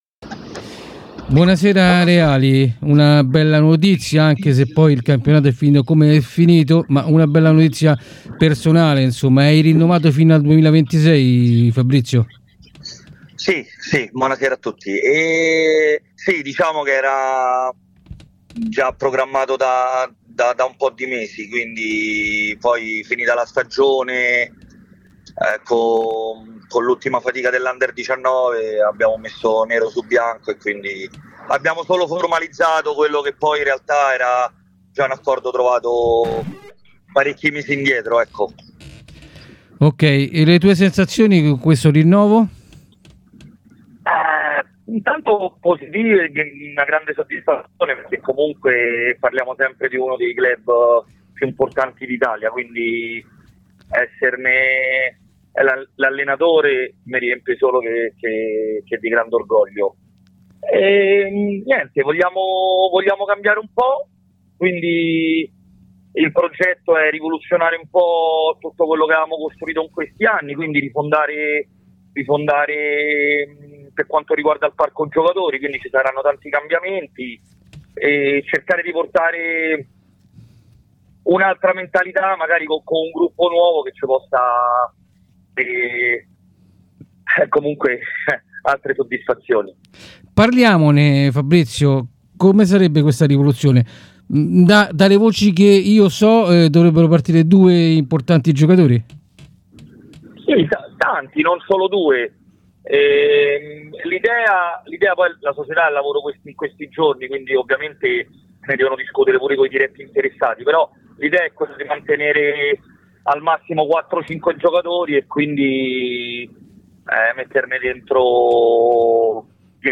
è intervenuto ai nostri microfoni